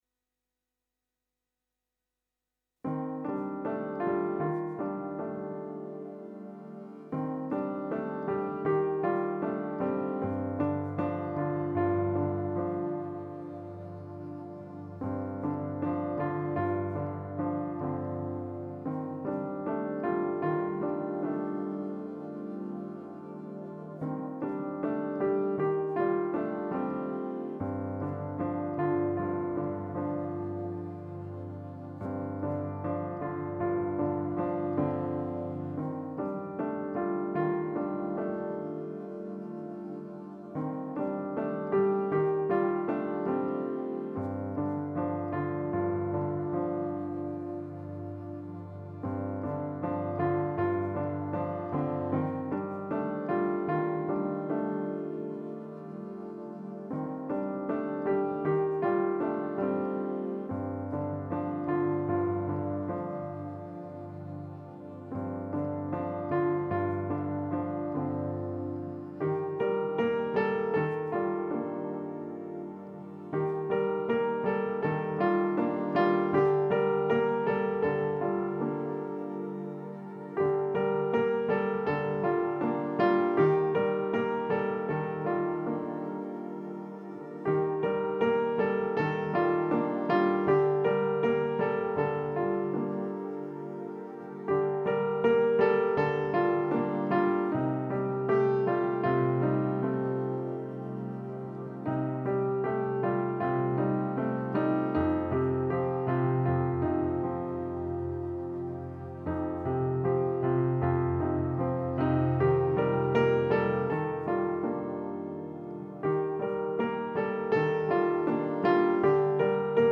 Sonidos de Piano
Piano Instrumental